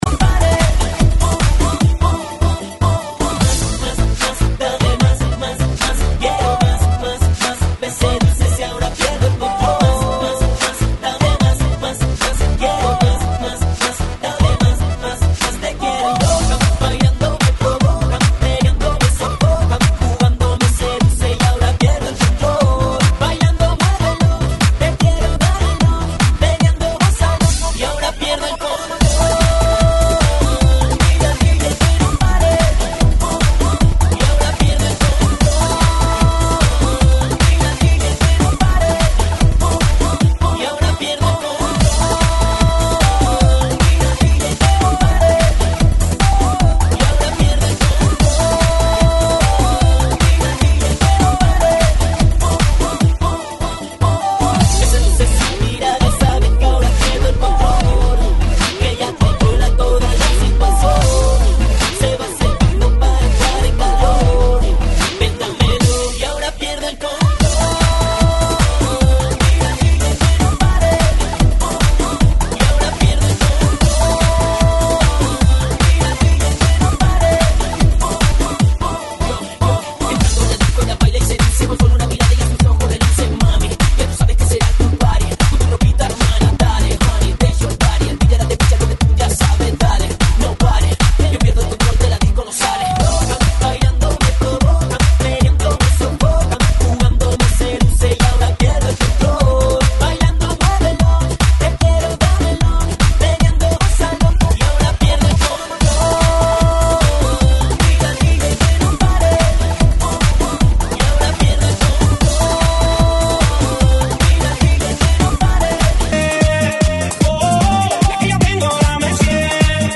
GENERO: REGGAETON